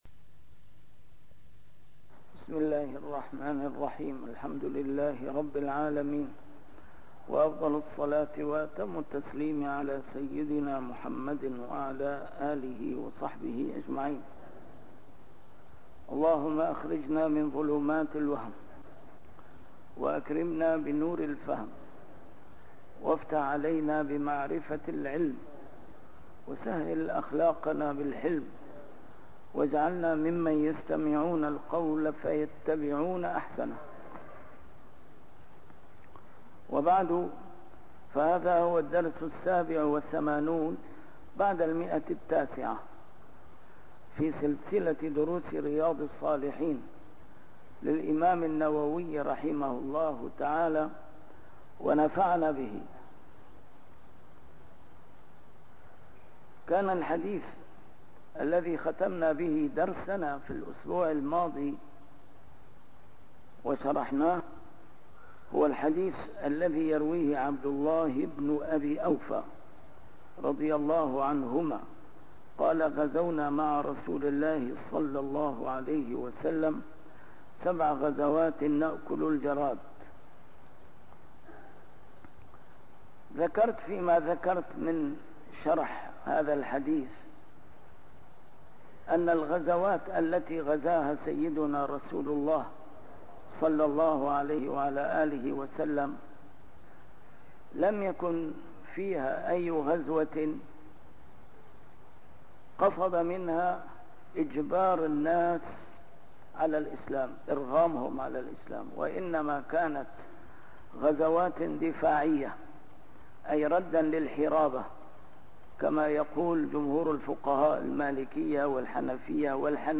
A MARTYR SCHOLAR: IMAM MUHAMMAD SAEED RAMADAN AL-BOUTI - الدروس العلمية - شرح كتاب رياض الصالحين - 987- شرح رياض الصالحين: بابُ المنثورات والمُلَح